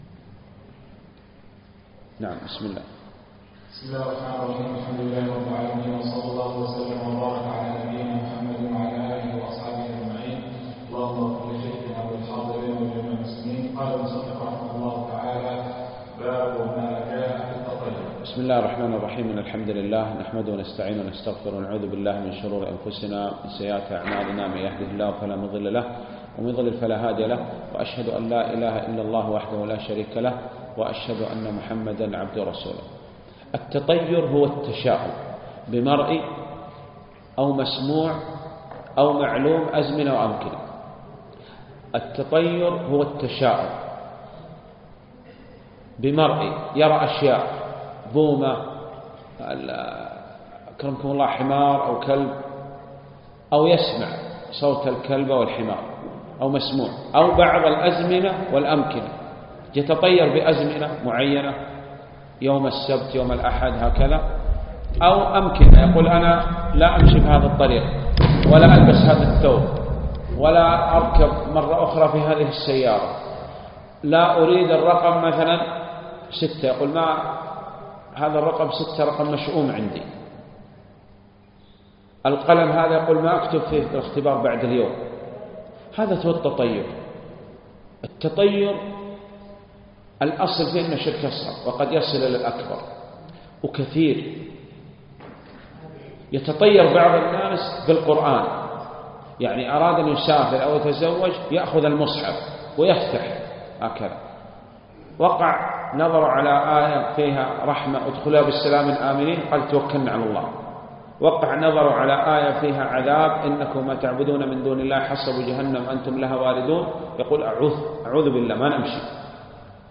الدرس الثالث